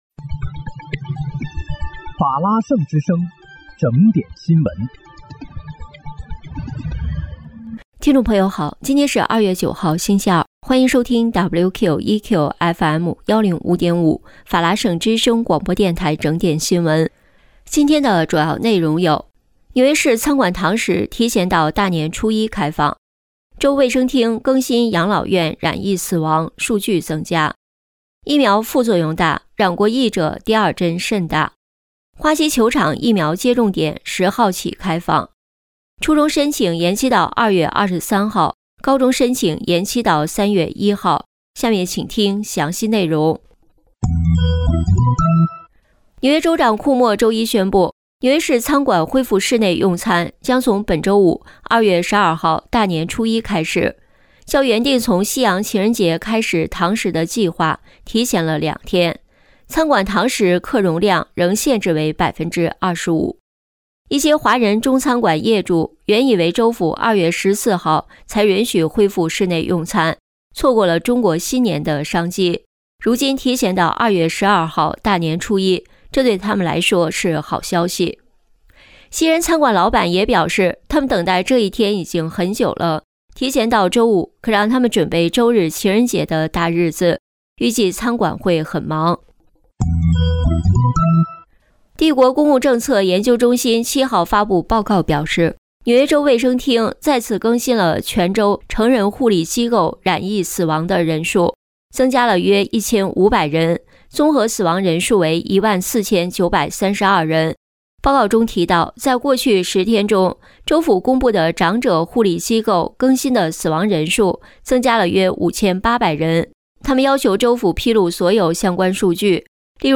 2月9日（星期二）纽约整点新闻
听众朋友您好！今天是2月9号，星期二，欢迎收听WQEQFM105.5法拉盛之声广播电台整点新闻。